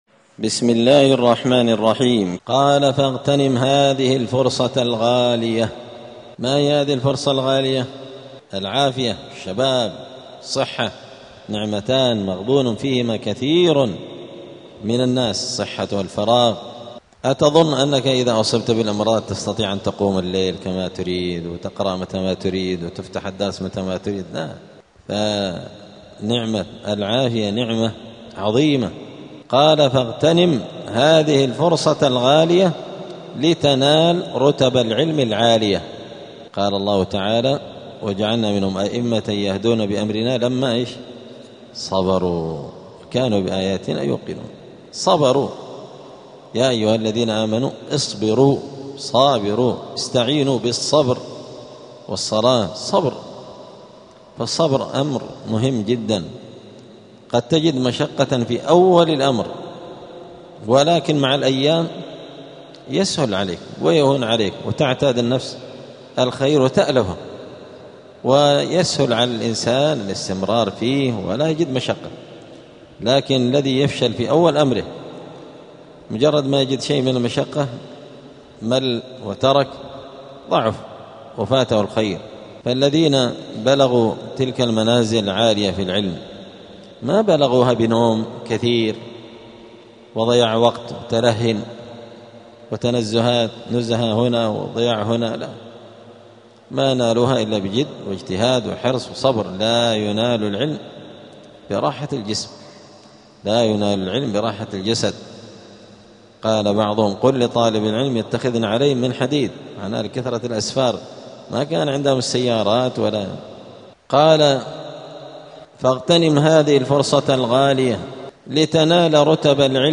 الجمعة 15 شوال 1447 هــــ | الدروس، حلية طالب العلم، دروس الآداب | شارك بتعليقك | 2 المشاهدات
دار الحديث السلفية بمسجد الفرقان قشن المهرة اليمن